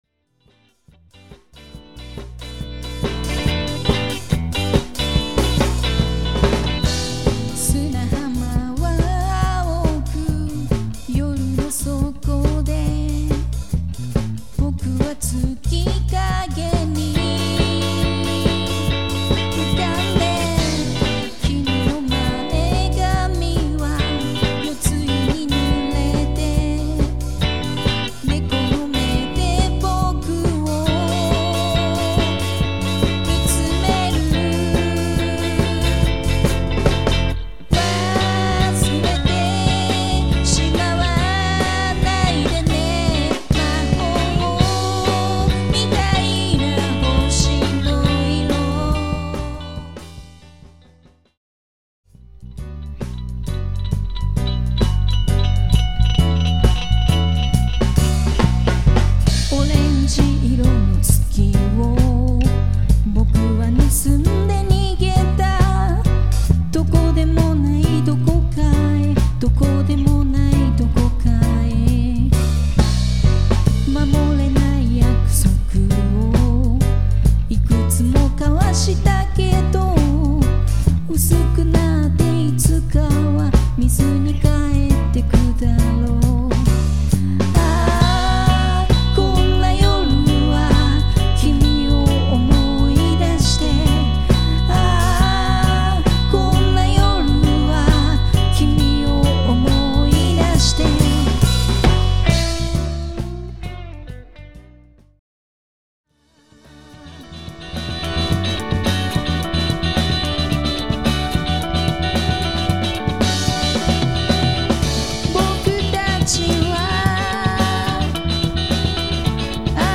6th - LiveCDR
三人編成になってからの初音源！
ダイジェスト試聴（5分4秒/mp3）